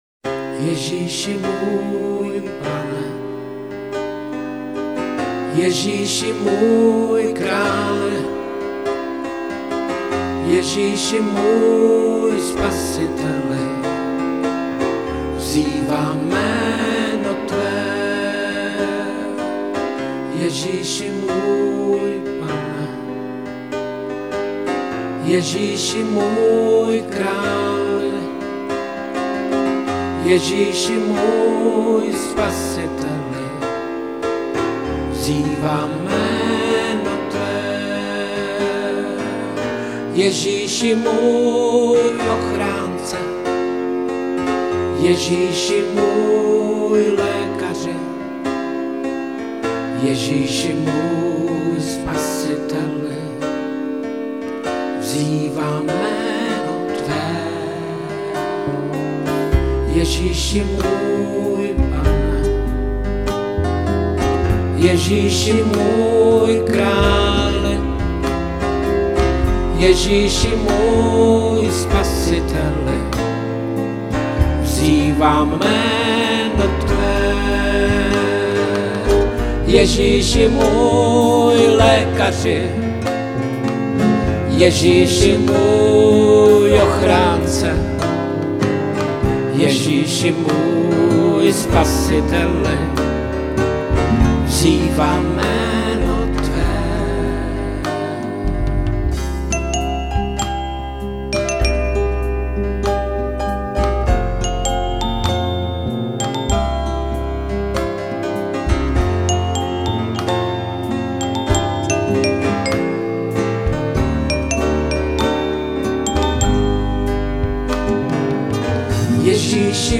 Písně ke chvále a uctívání